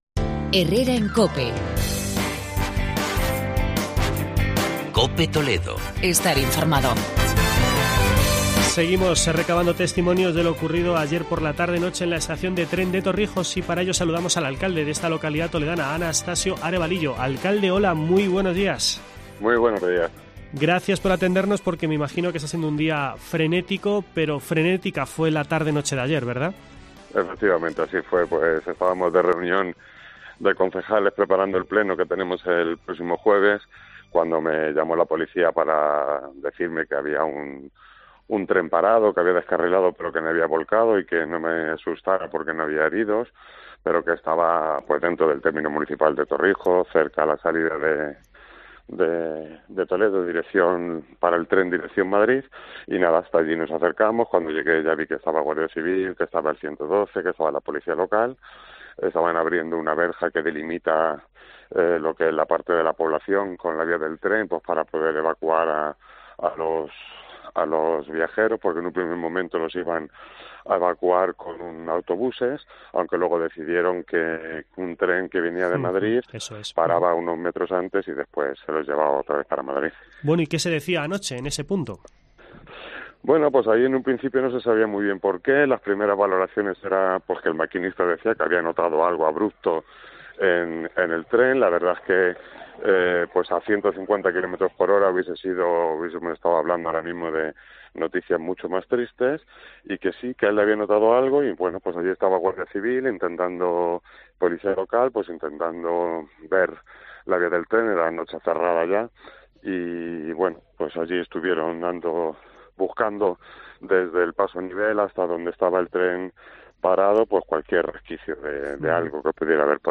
Entrevista con el alcalde de Torrijos